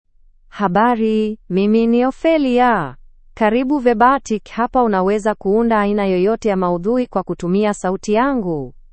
Ophelia — Female Swahili (Kenya) AI Voice | TTS, Voice Cloning & Video | Verbatik AI
Ophelia is a female AI voice for Swahili (Kenya).
Voice sample
Listen to Ophelia's female Swahili voice.
Ophelia delivers clear pronunciation with authentic Kenya Swahili intonation, making your content sound professionally produced.